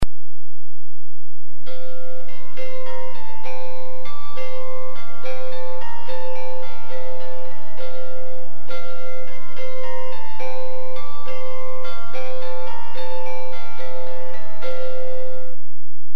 Plucked Psaltery Sound Clips
Each string on this type of psaltery is plucked either with the player's fingernails or with a plectrum.
The example in the photograph, which you can also hear on the sound clip, is a Russian psaltery called a cimbala (from the word cimbalum, which was sometimes used to describe the early family of hammered dulcimers).